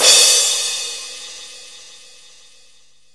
Crashes & Cymbals
pcp_cymbal01.wav